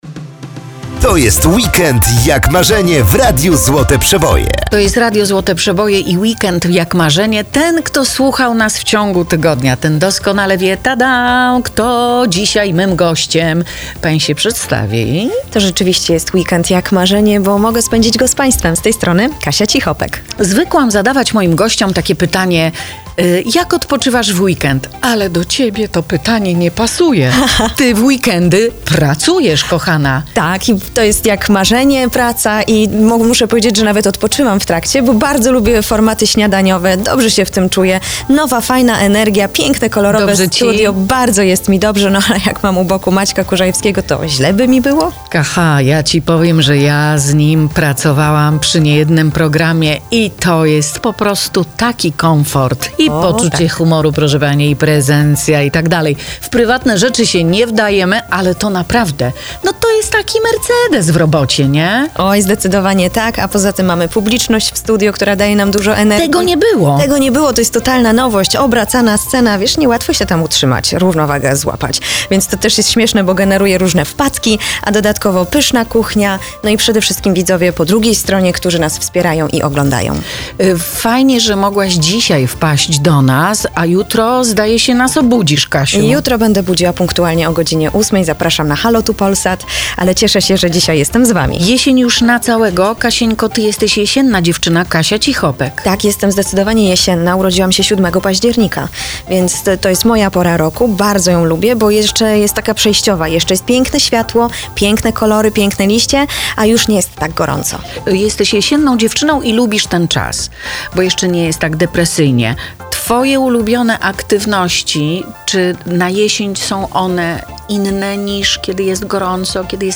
Katarzyna Cichopek była gościnią Marzeny Rogalskiej w podcaście "Weekend jak marzenie" w Radiu Złote Przeboje.